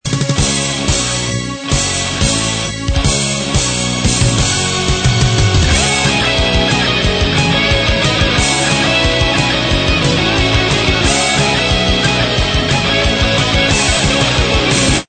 heavy métal